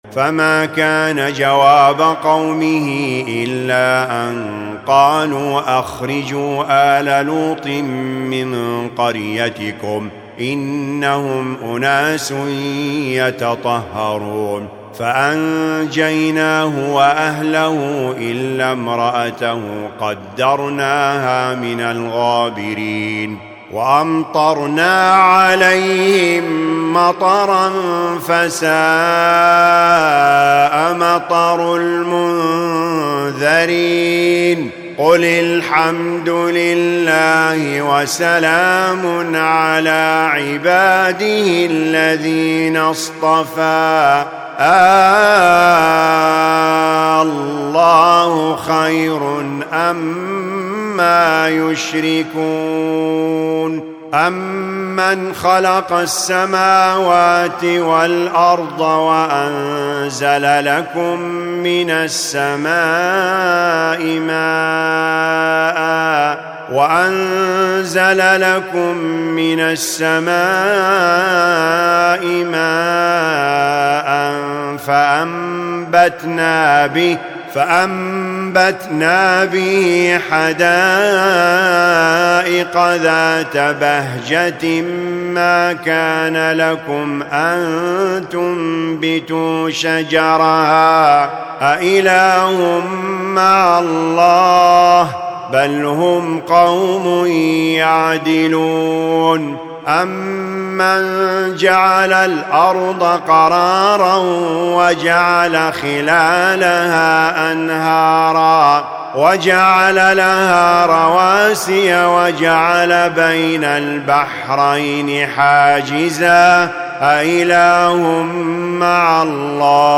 الجزء العشرون : سور النمل 56-93 القصص كاملة العنكبوت 1-45 > المصحف المرتل